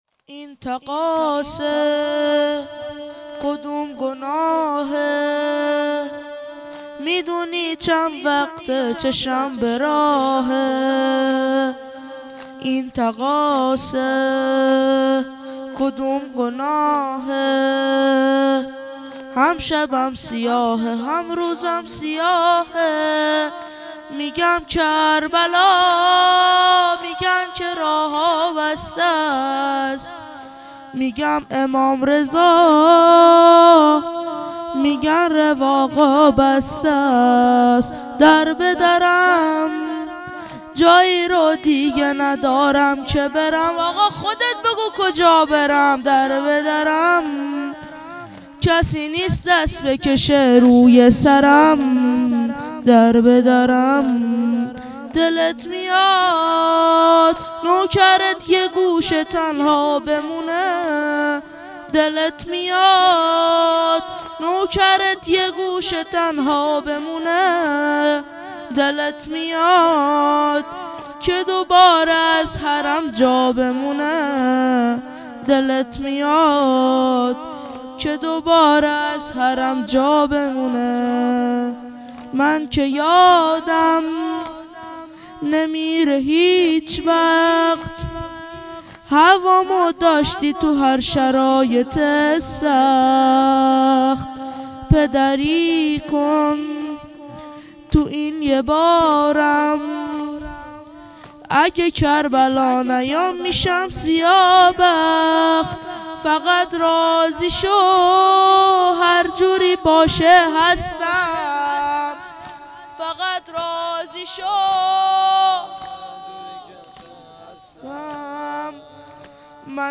زمینه - این تقاصه کدوم گناهه
شب چهارم - دهه اول محرم 1400